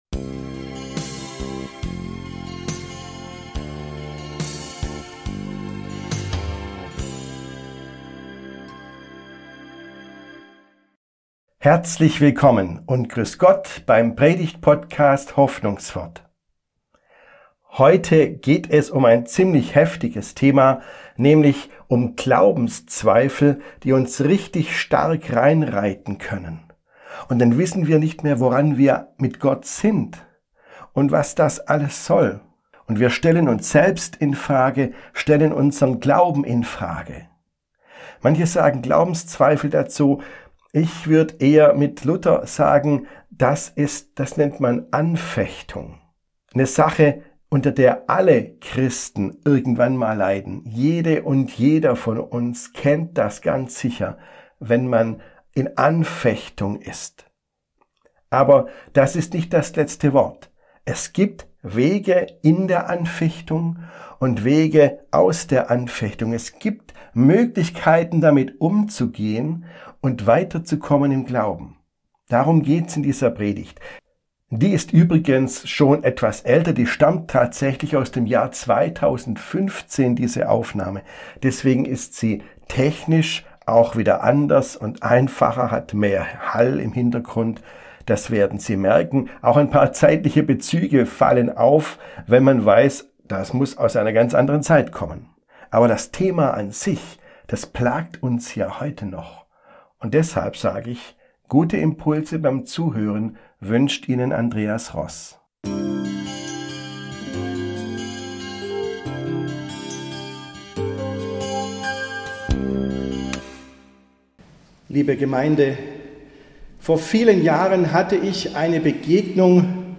Anfechtung: Wenn Glaube zwischen den Fingern zerrinnt ~ Hoffnungswort - Predigten